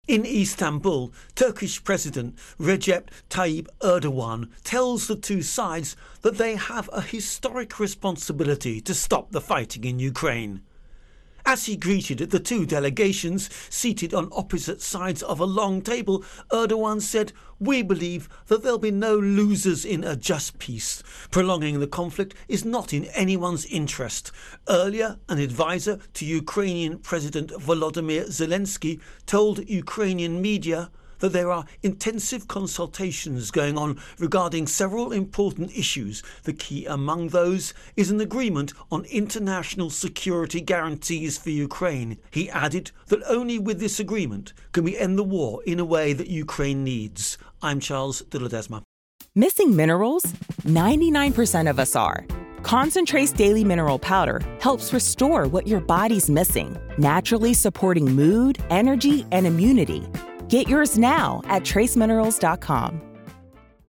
Russia Ukraine War Turkey Talks Intro and Voicer